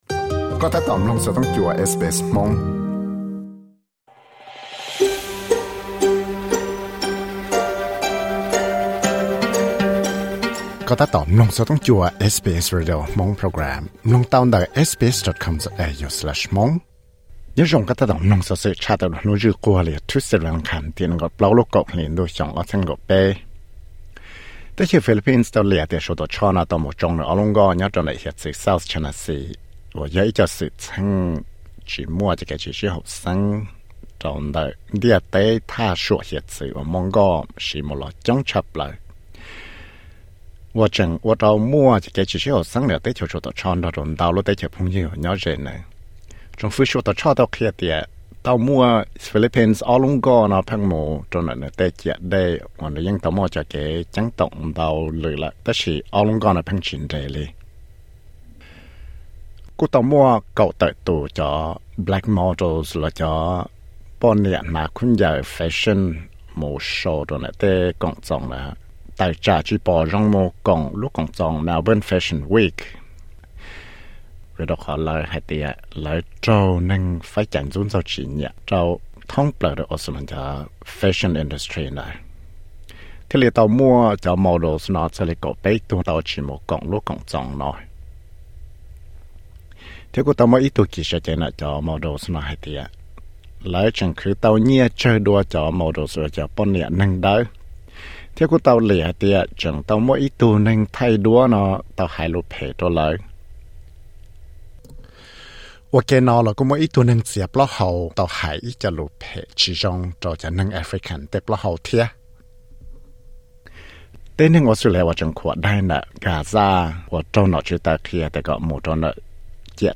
Studio